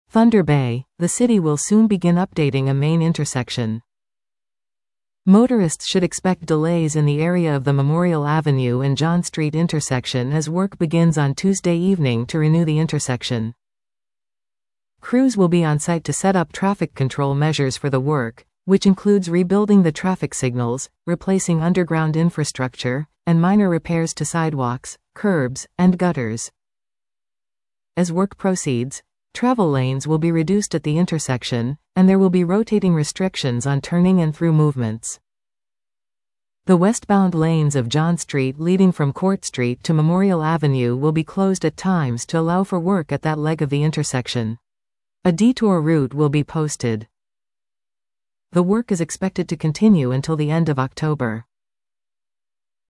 Listen to this article 00:00:52 THUNDER BAY — The city will soon begin updating a main intersection.